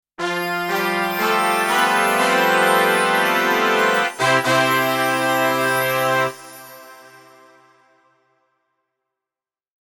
Festive Winning Brass Sound Effect
Celebrate success with this festive winning brass sound effect, featuring bright brass and gentle chimes for a joyful mood.
Festive-winning-brass-sound-effect.mp3